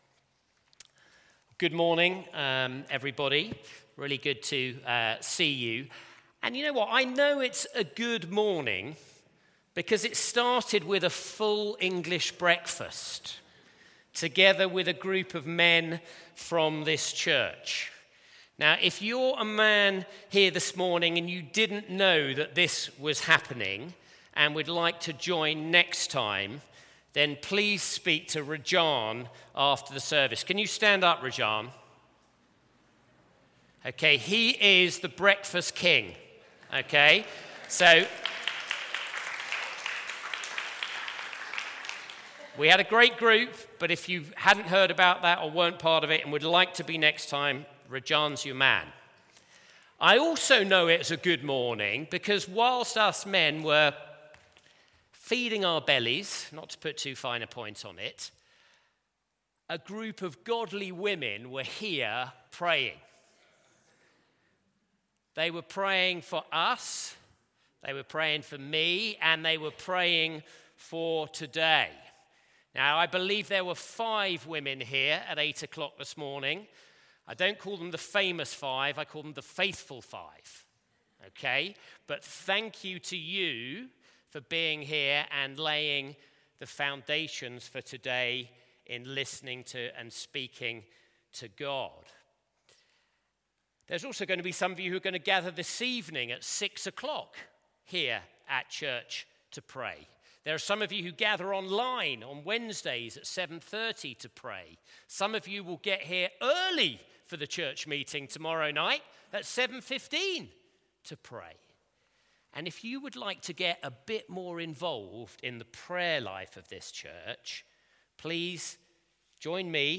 Sunday Service
Series: Teachings of Jesus Theme: Do not worry Sermon